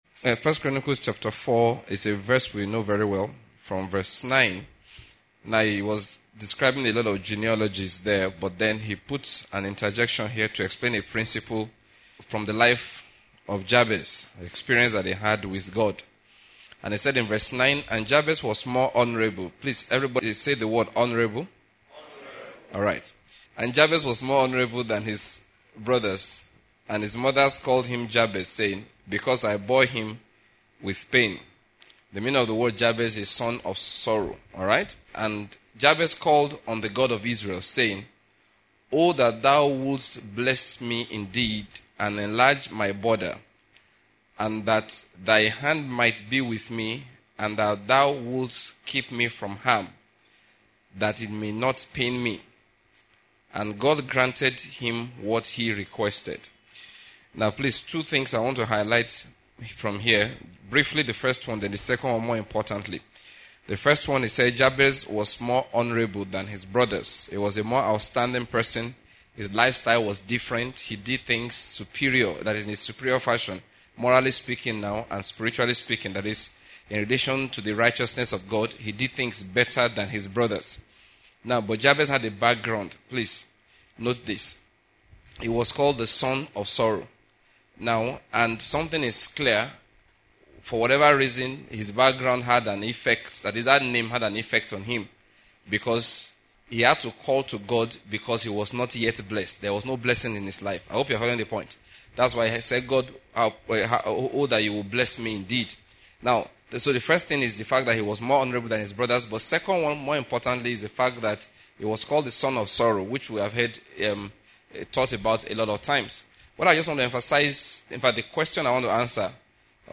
audio message